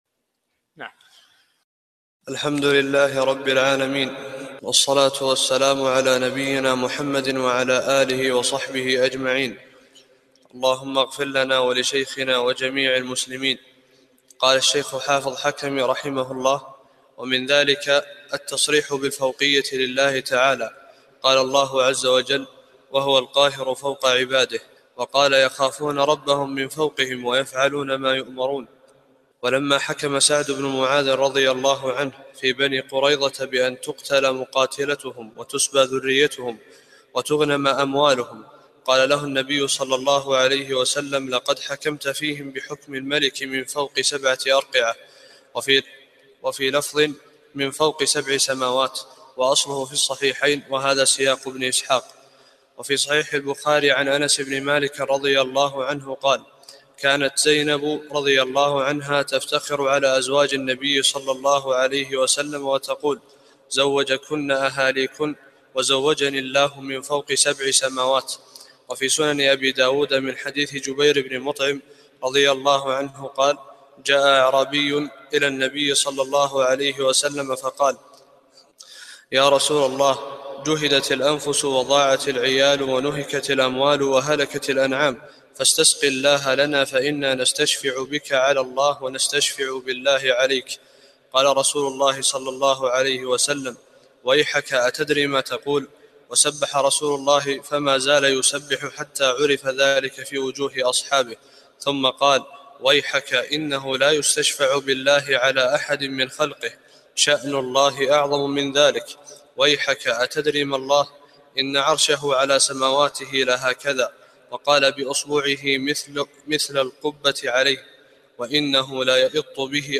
16 - الدرس السادس عشر